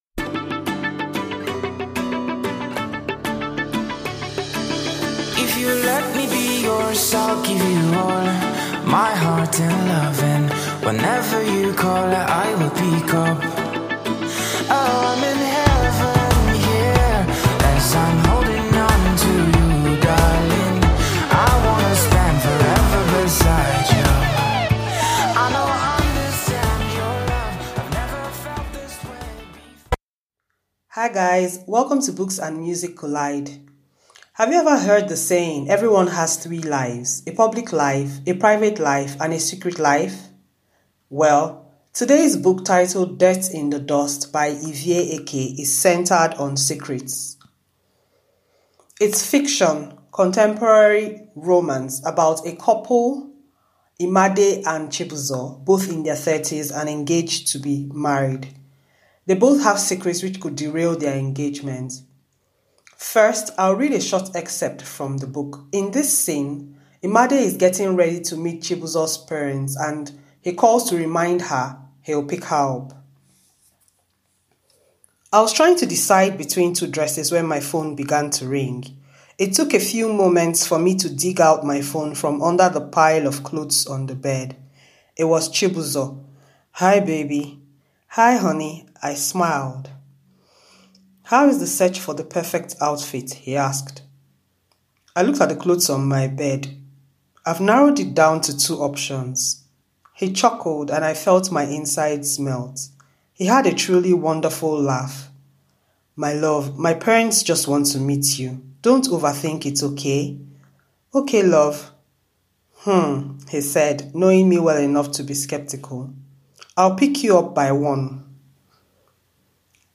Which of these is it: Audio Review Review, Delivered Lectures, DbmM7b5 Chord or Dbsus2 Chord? Audio Review Review